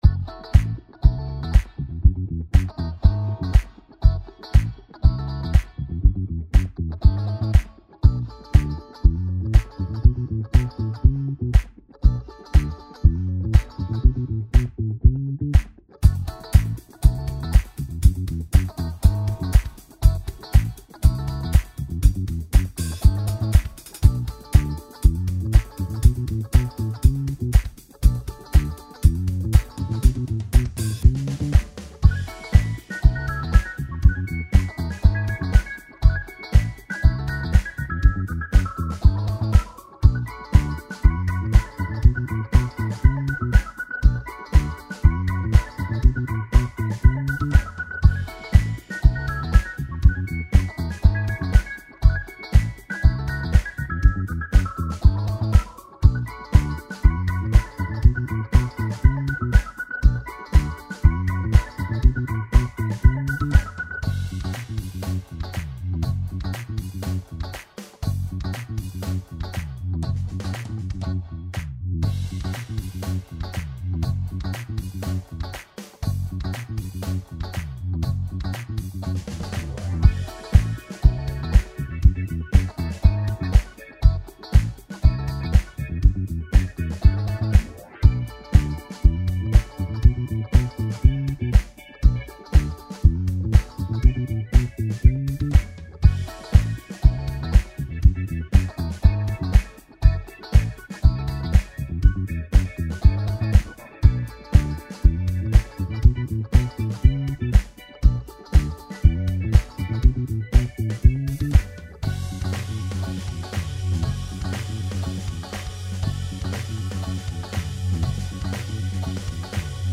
gimmick - guitare - funky - ete - vacances